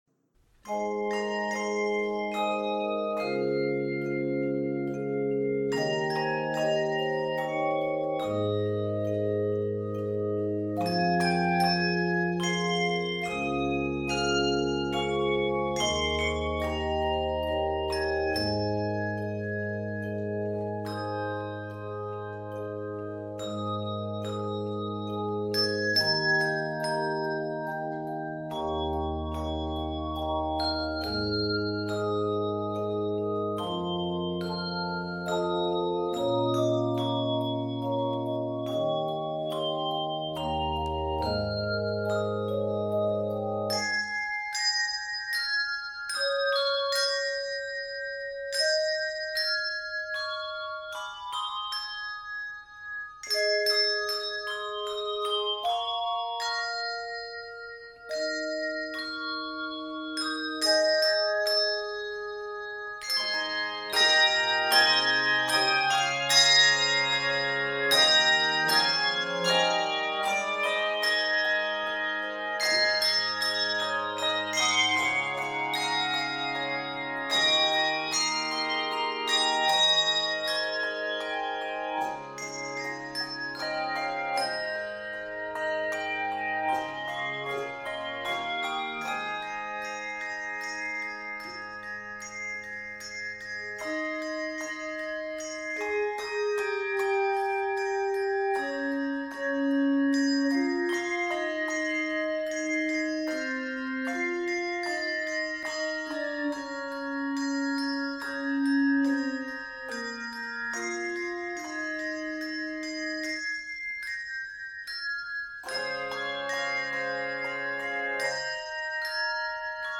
Keys of Ab Major and C Major.
Octaves: 3-6